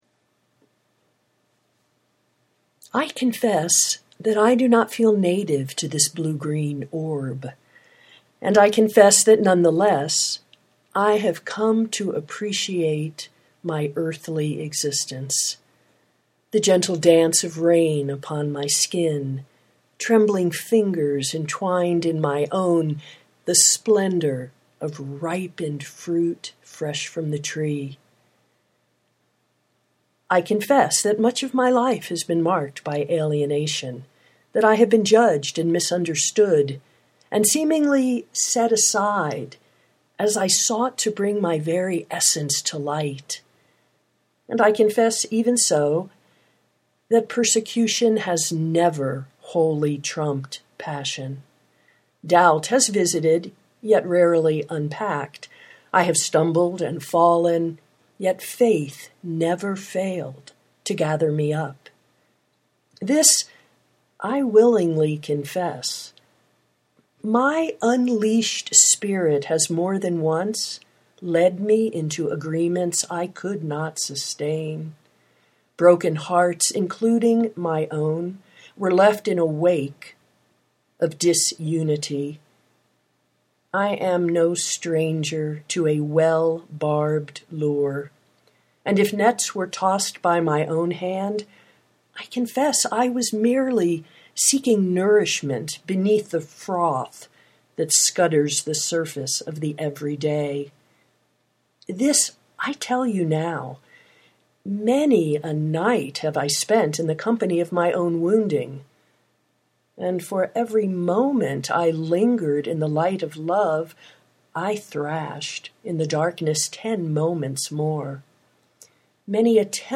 I was called to record an audio rendering of this poetry,